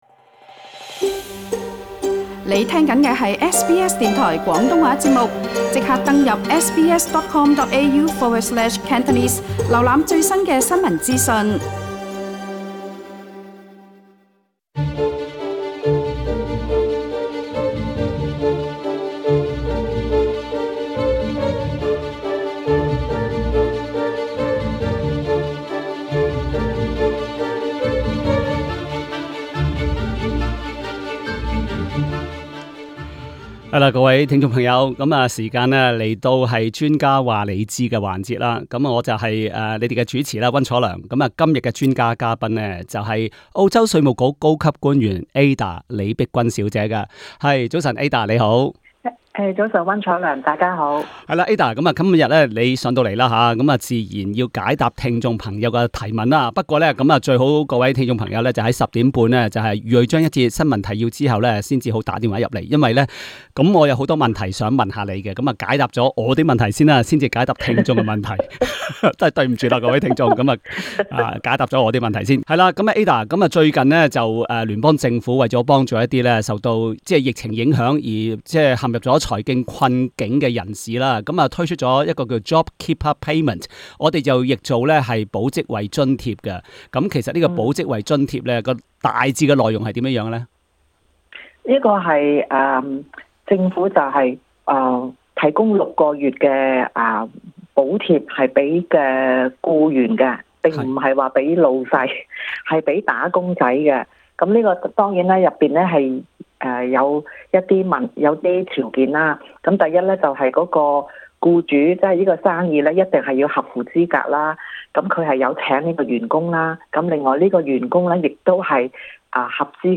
一位聽眾來電表示，他是老闆，並登記了「保職位」補貼計劃，可是稅務局指示他需墊付每兩週 1,500 元給予合資格的僱員，他自己沒有足夠的現金流，也不想貸款來先付員工，他問他該怎辦。